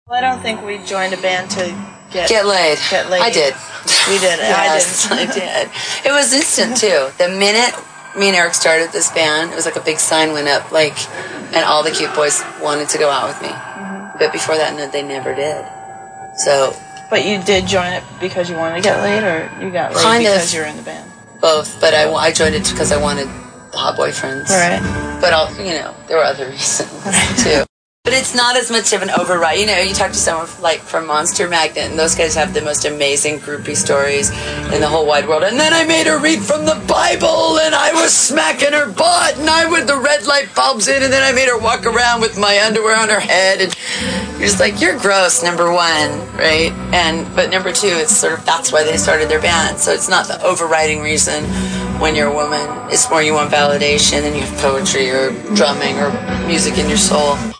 Interview on French TV, 1999